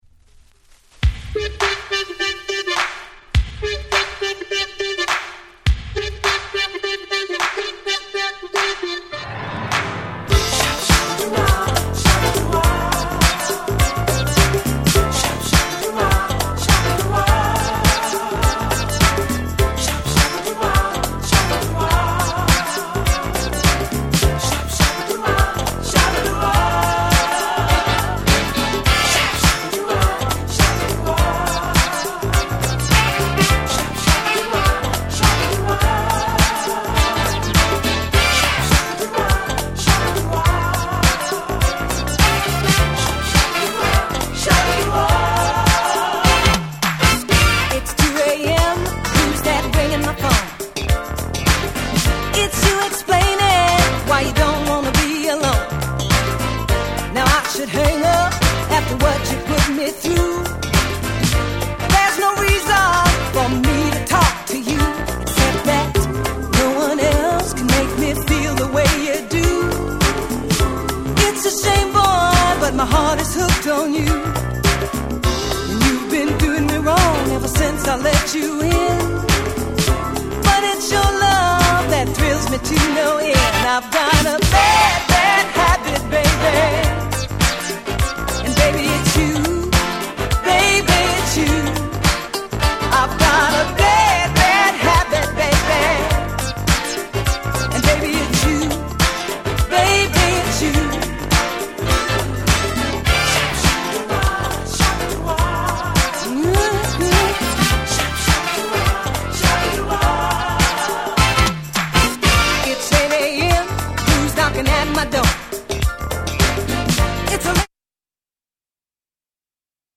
鉄板Dance Classic !!